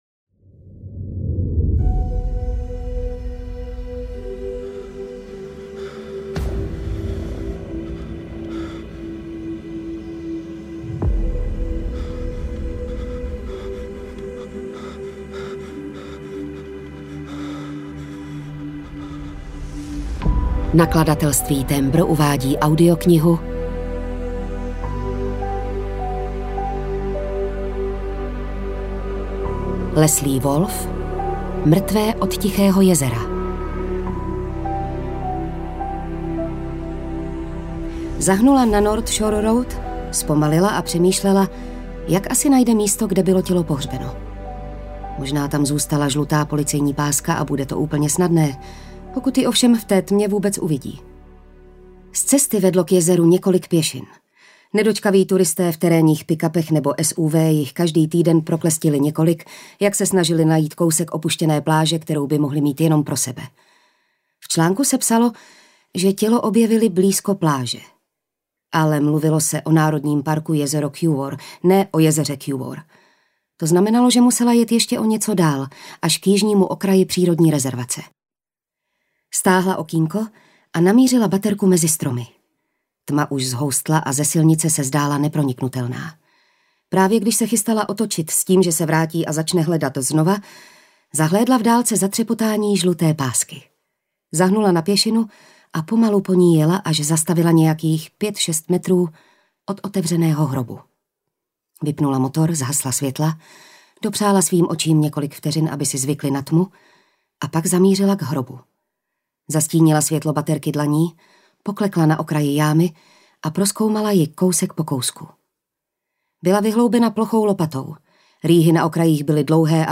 Mrtvé od Tichého jezera audiokniha
Ukázka z knihy